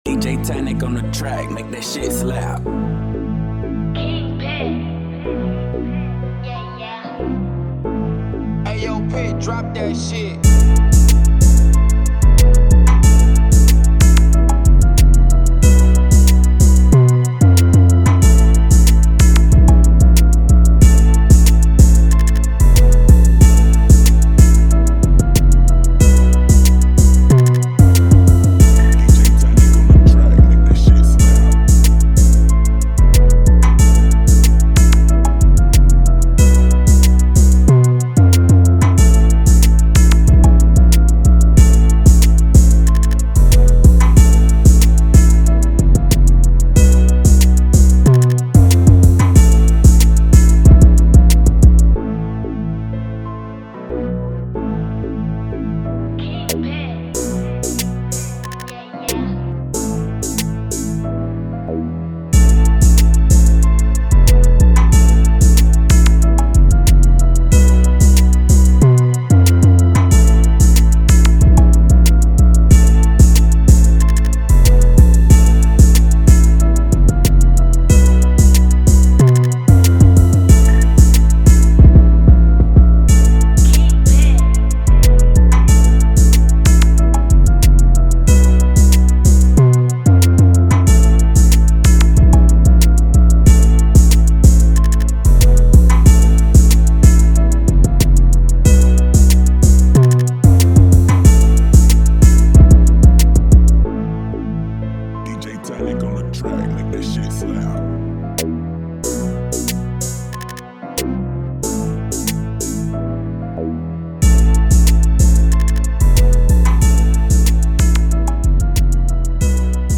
Trap Instrumentals